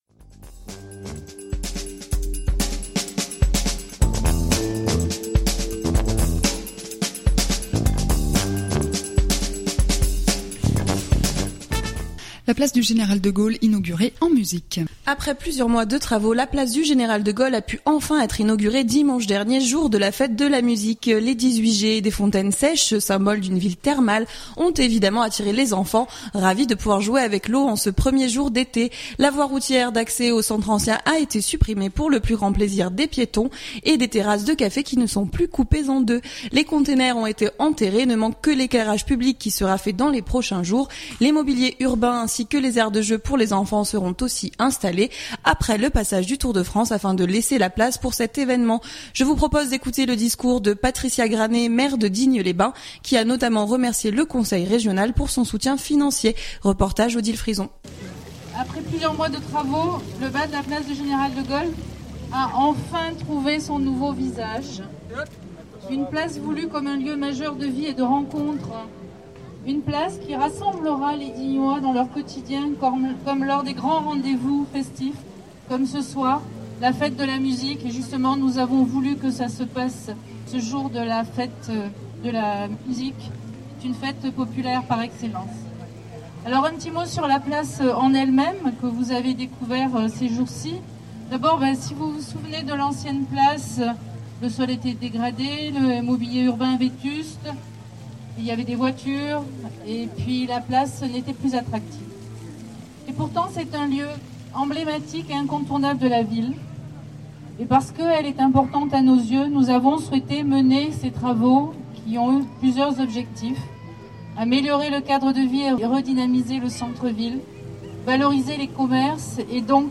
Je vous propose d’écouter le discours de Patricia Granet, maire de Digne les bains, qui a notamment remercié le conseil régional.